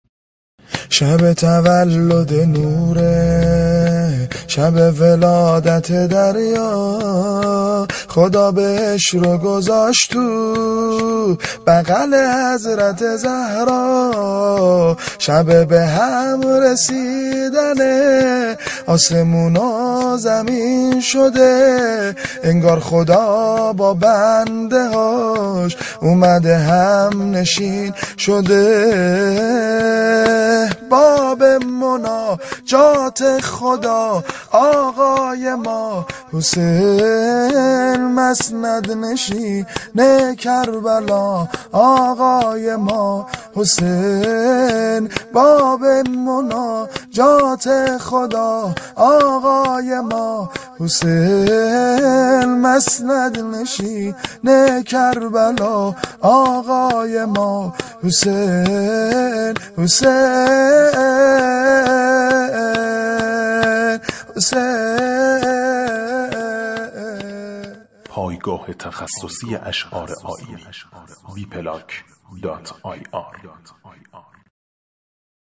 شور ، سرود